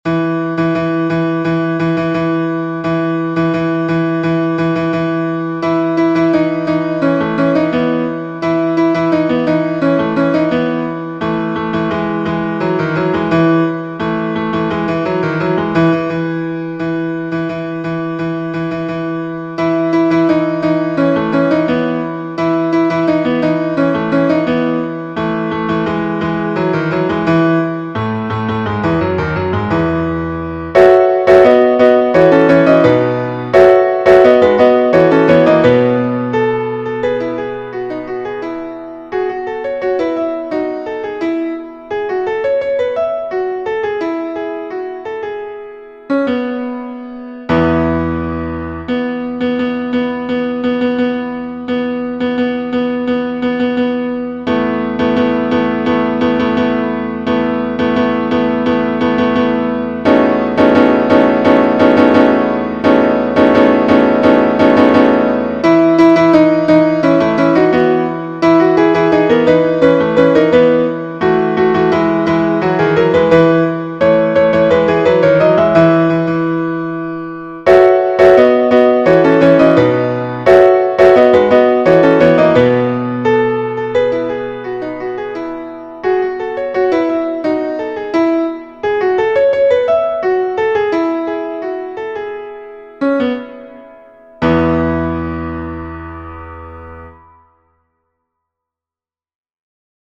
MP3 versions piano
Version Piano